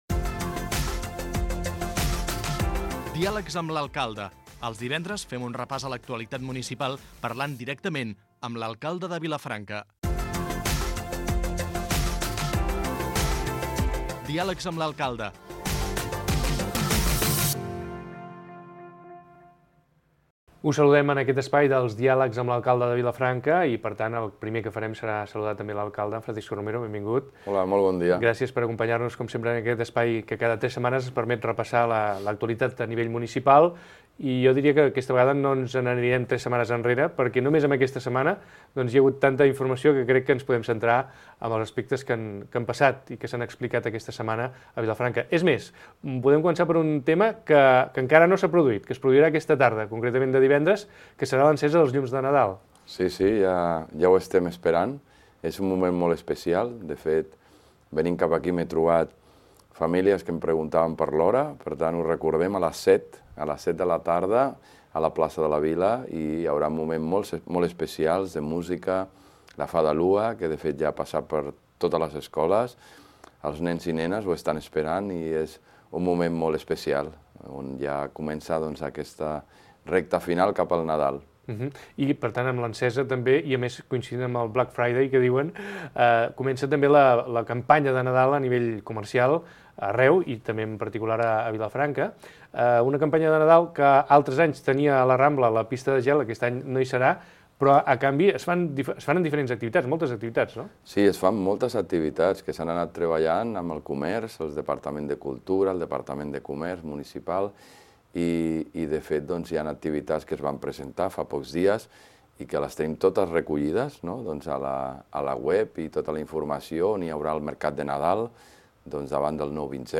Entrevista amb l'alcalde Francisco Romero